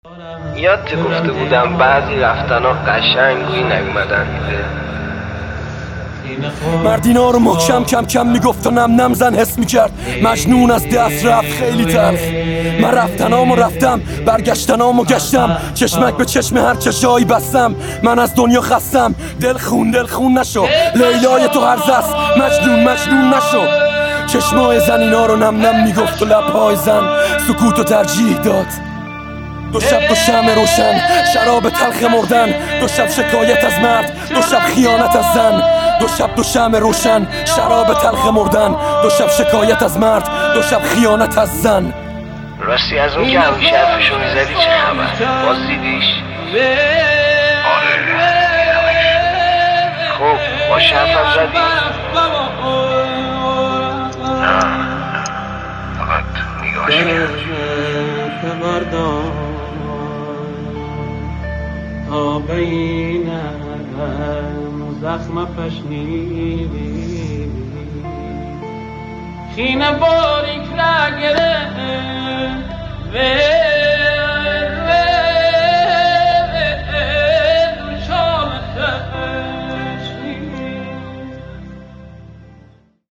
*ریمیکس منشتر شده به صورت دکلمه می باشد.